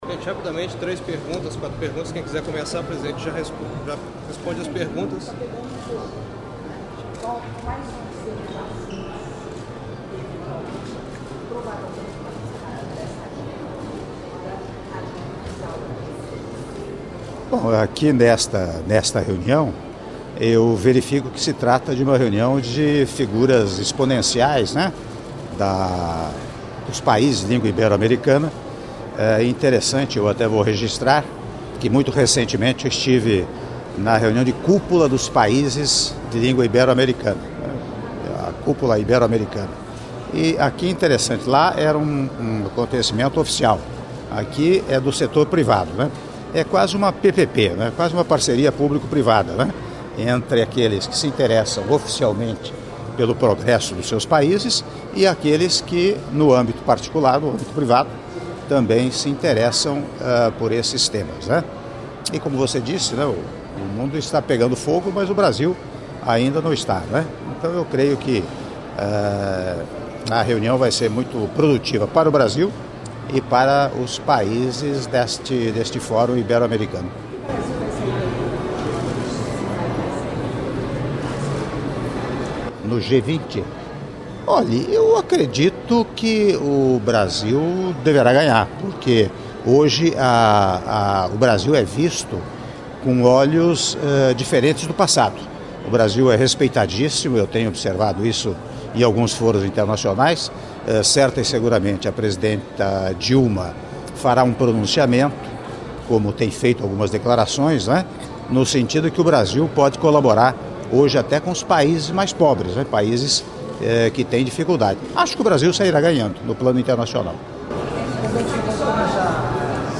Entrevista coletiva concedida pelo Presidente da República em exercício, Michel Temer, antes do jantar de abertura do Foro Ibero-América – Edição 2011 - Rio de Janeiro/RJ